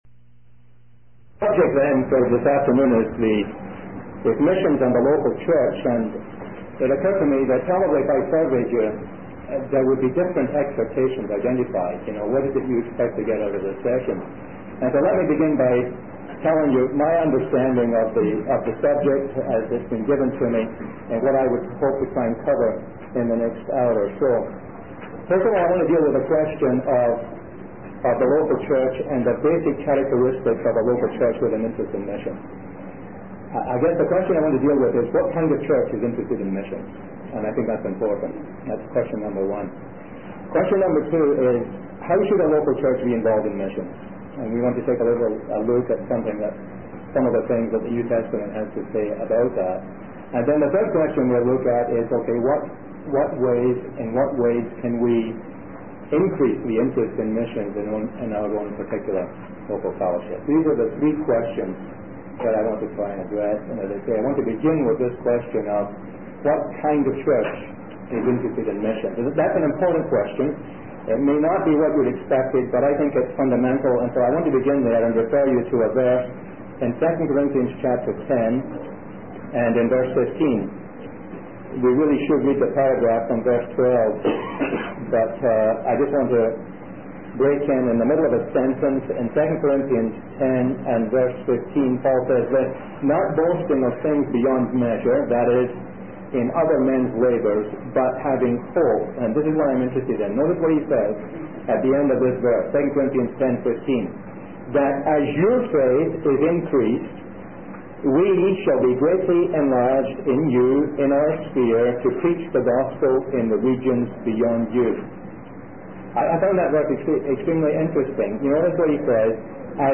In this sermon, the speaker discusses the importance of being involved in missions and supporting missionaries. He shares personal experiences of having missionaries stay in his home and the impact it had on his family. The speaker emphasizes the need for churches to actively engage in mission work and teaches that it is a biblical principle.